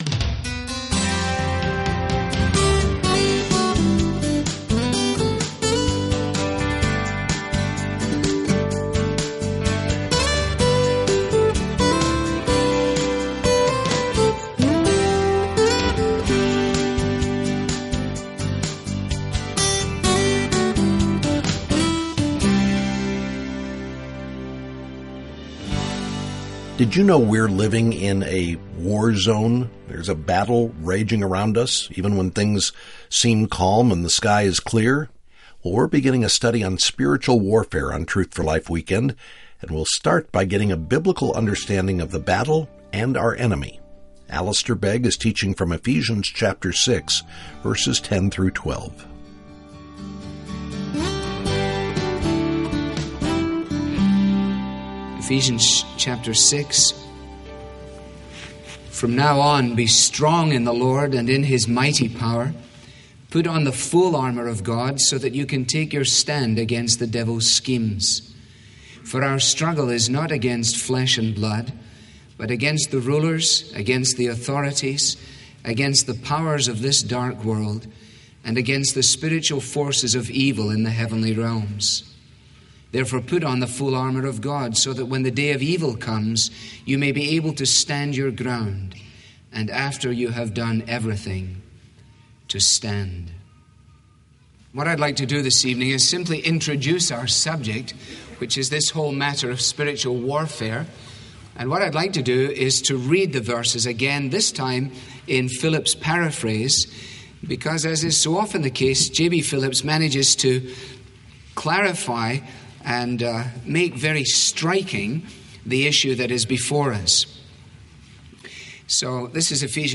This listener-funded program features the clear, relevant Bible teaching of Alistair Begg.